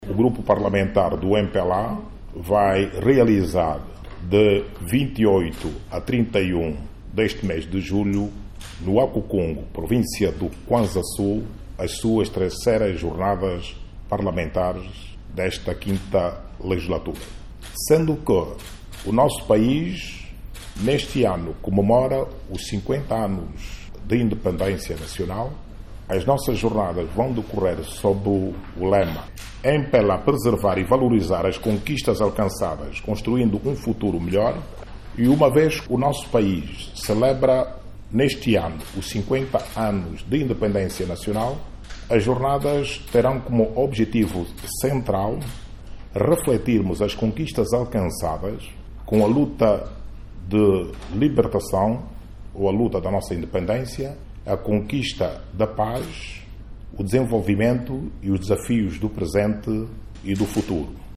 Em conferência de imprensa, o primeiro Secretário do Grupo Parlamentar do MPLA, Jorge Ribeiro Uefu, disse que o objectivo é reflectir sobre as vitórias da luta pela independência e reforçar o compromisso com o desenvolvimento do país.